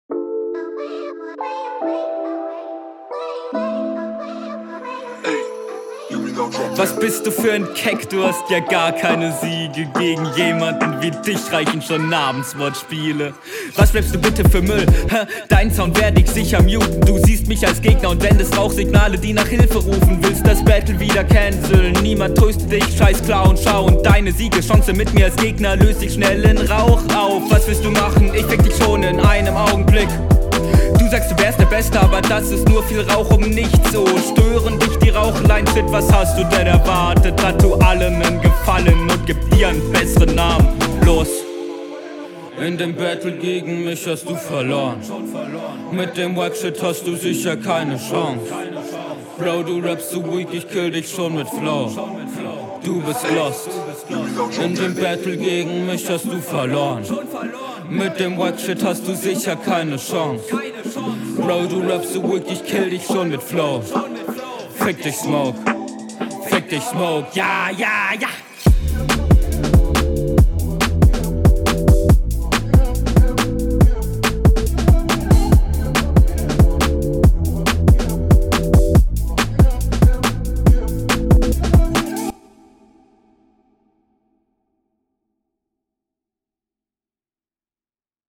cooler beat. flow und rap könnten noch cooler und routinierter sein aber das ist definitiv …
Sehr schöner Beatpick , Mische klingt angenehm und clean.